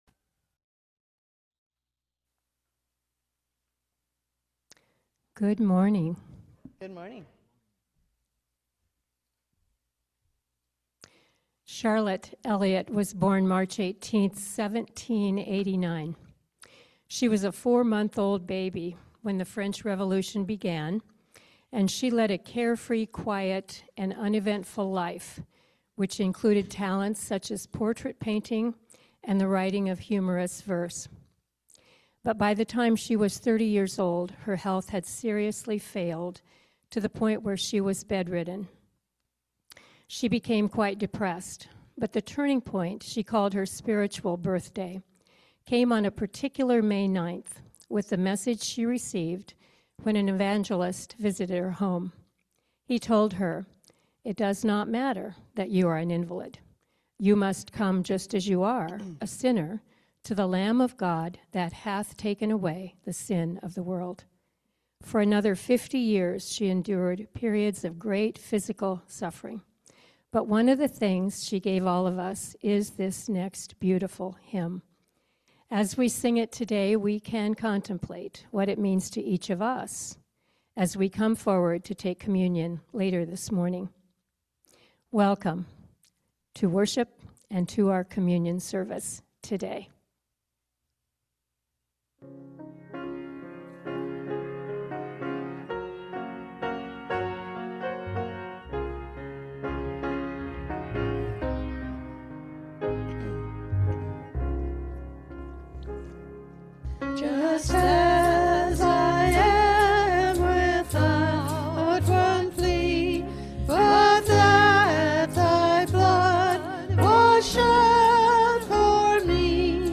Worship-February-1-2026-Voice-Only.mp3